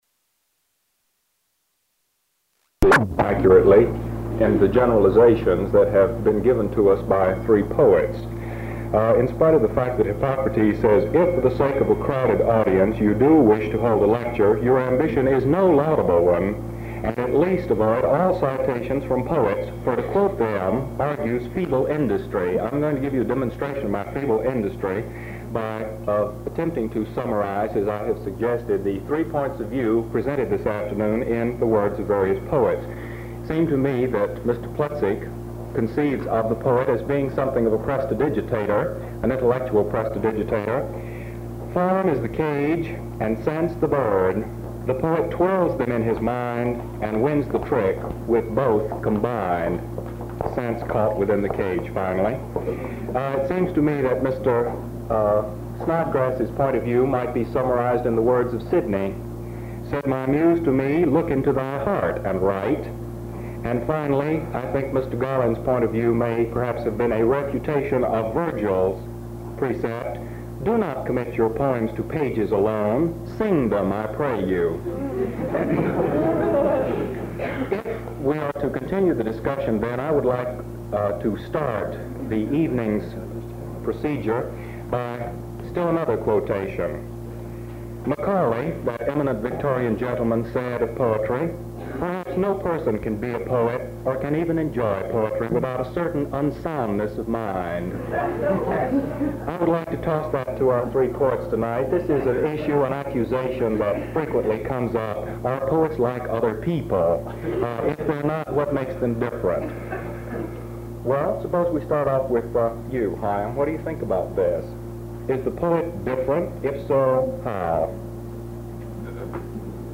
Speeches